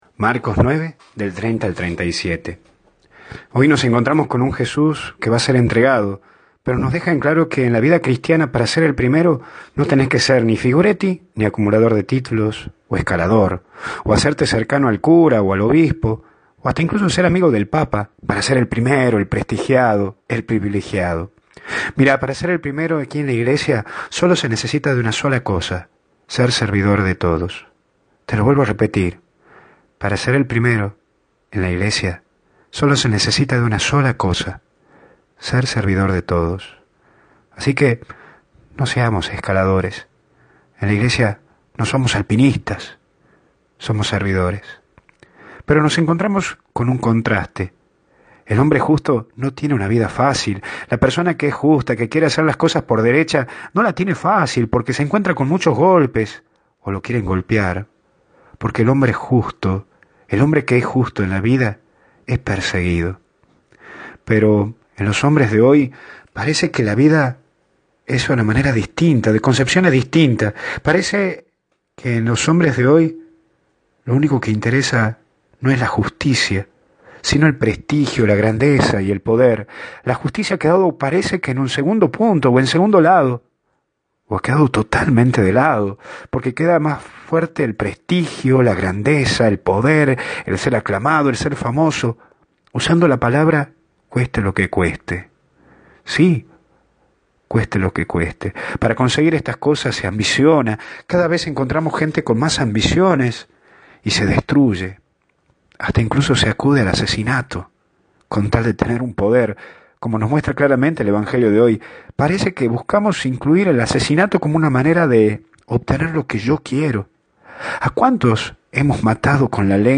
Meditación Diaria
Esta mal grabado pasaron el de ayer .